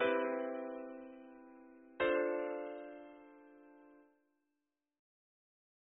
ピアノ